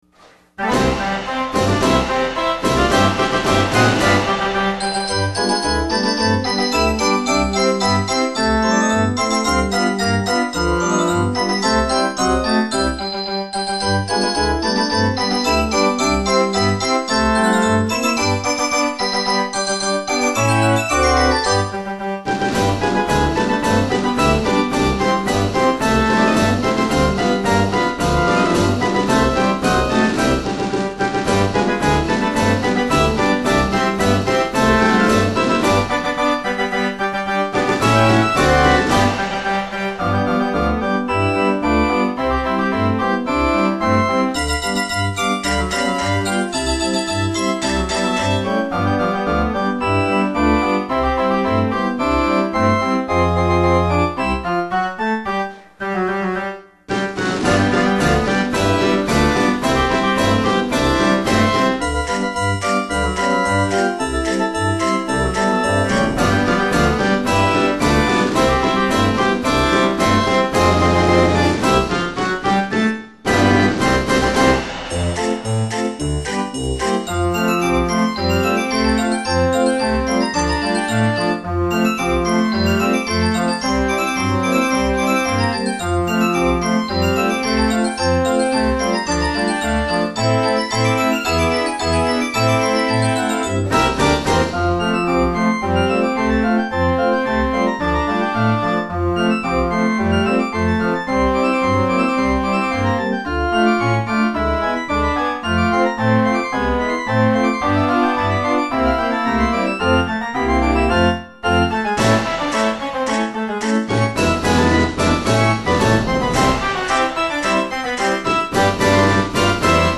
March-TS